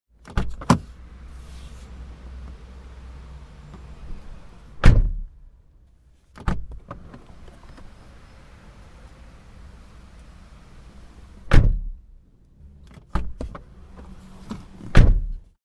Звуки двери машины
Атмосферный звук открытия и закрытия двери водителем снова